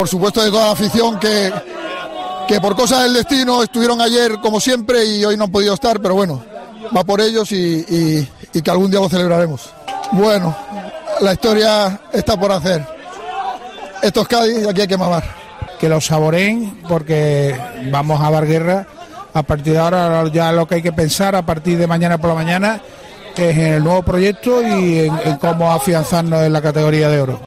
Cervera, que fue jaleado por sus jugadores en un ambiente festivo, recordó que después del confinamiento a todos los equipos «les ha costado muchísimo porque todos están muy bloqueados.